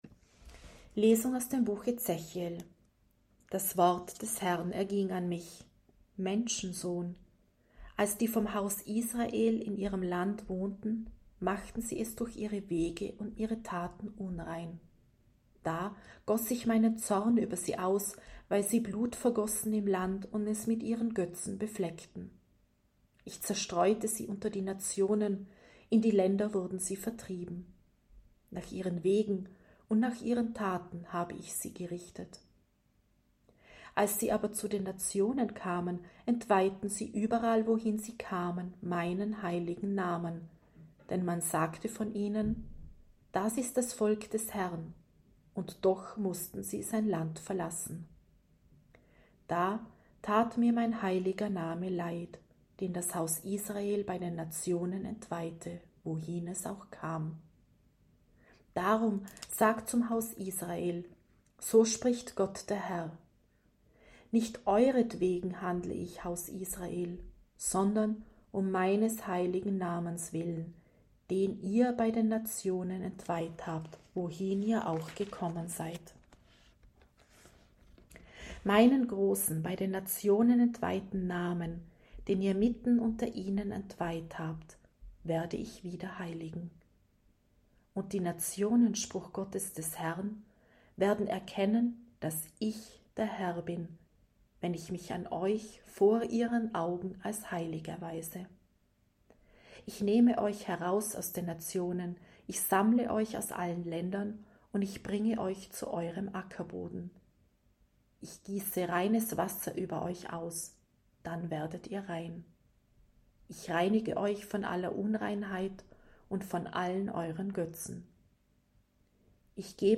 C-Osternacht-7.-Lesung.mp3